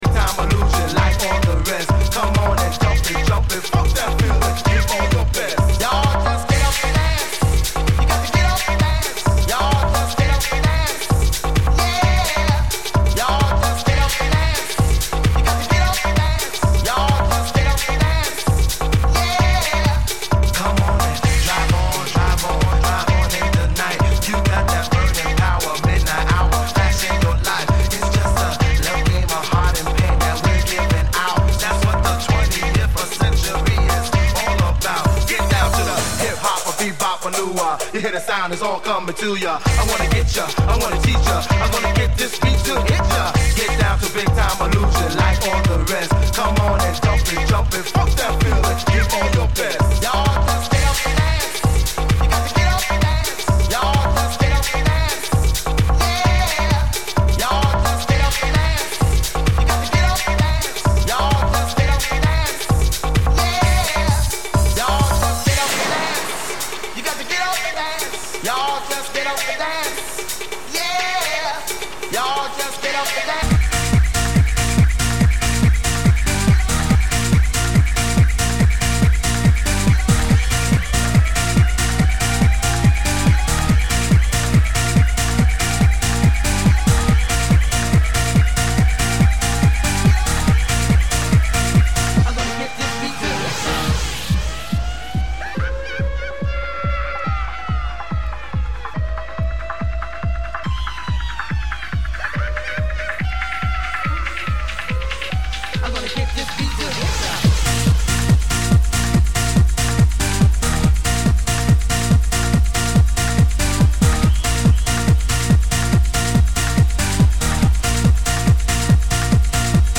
Genre: Trance